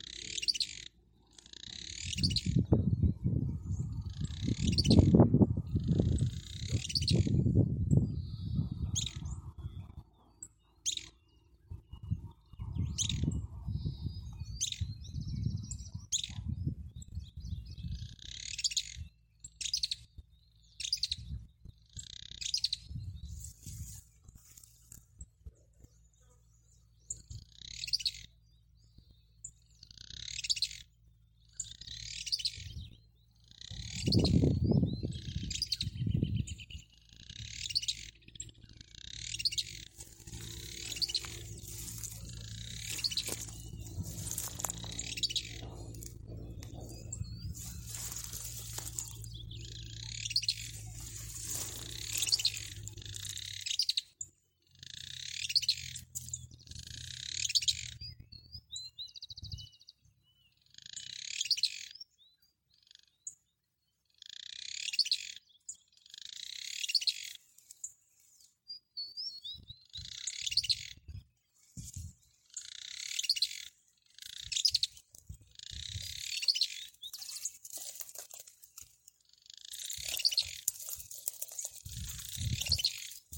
Barulhento (Euscarthmus meloryphus)
Nome em Inglês: Fulvous-crowned Scrub Tyrant
Condição: Selvagem
Certeza: Fotografado, Gravado Vocal